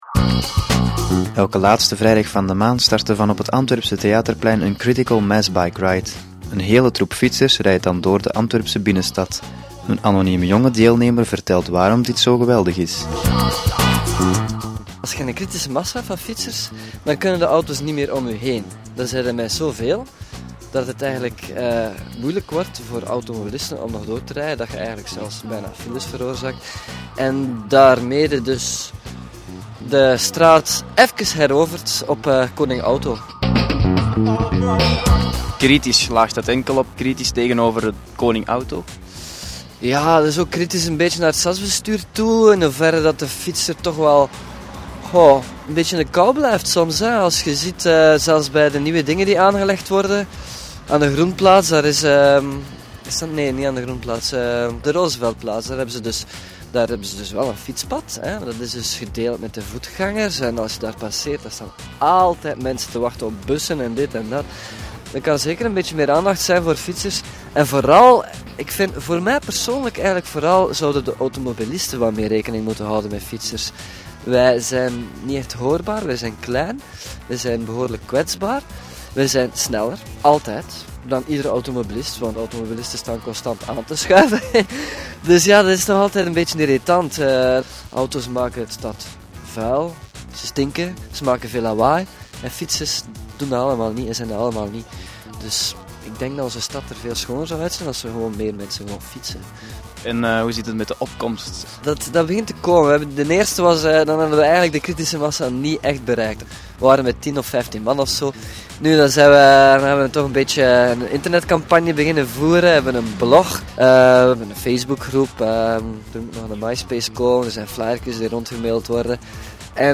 Beluister een gesprek met een anonieme jonge deelnemer aan de Antwerpse Critical Mass Ride Bike.